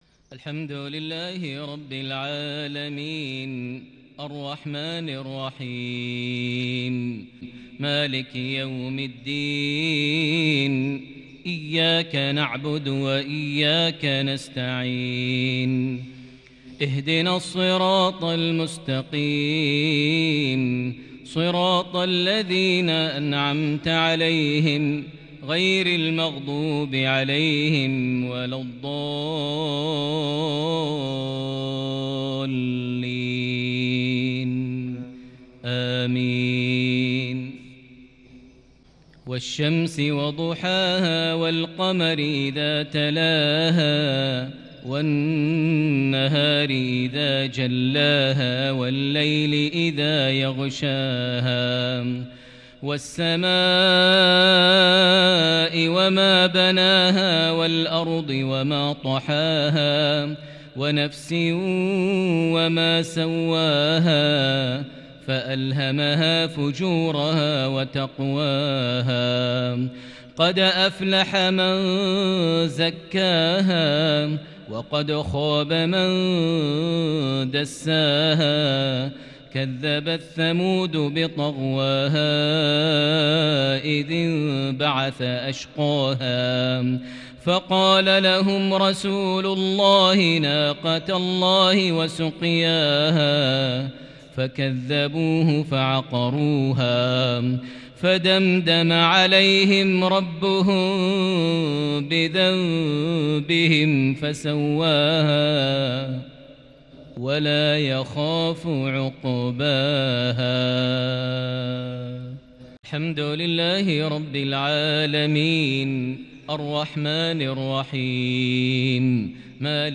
مغربية فريدة للغاية بالكرد البديع لسورتي الشمس - الليل | 25 ربيع الأول 1442هـ > 1442 هـ > الفروض - تلاوات ماهر المعيقلي